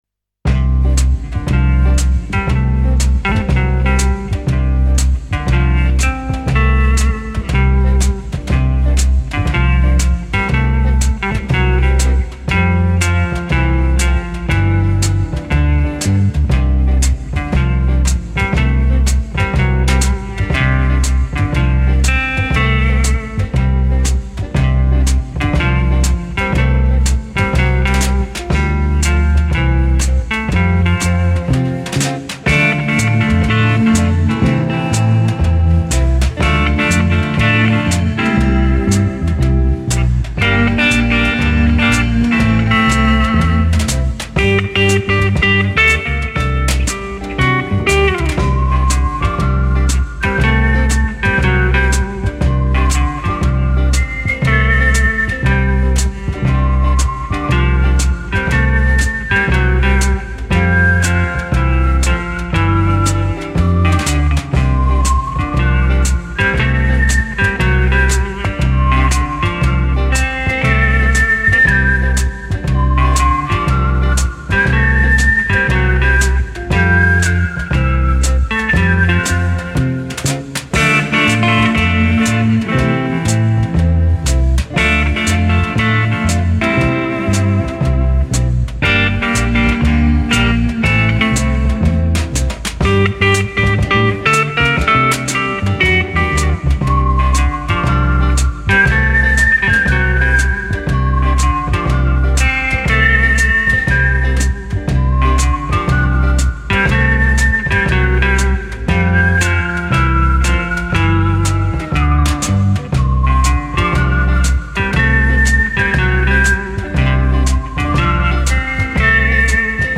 инструментальная группа 60-х годов XX века.